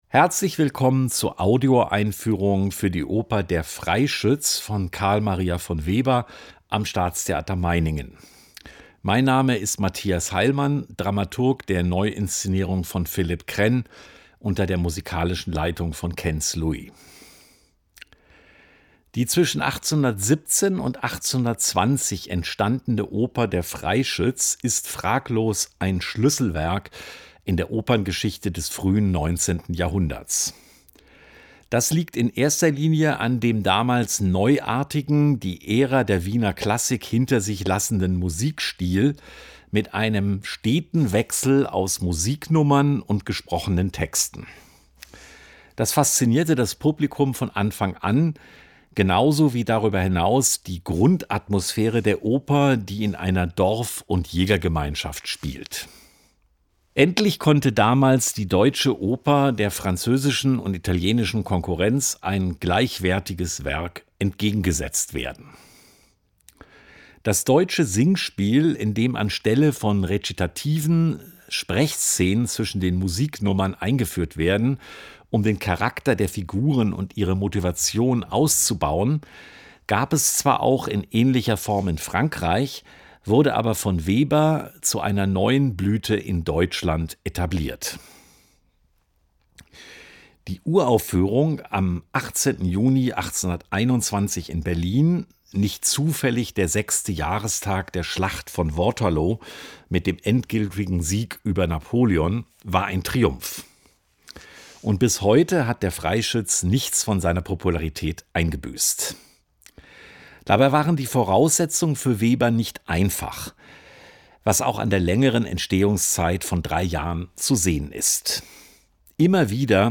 Audioeinführung_Der Freischütz.mp3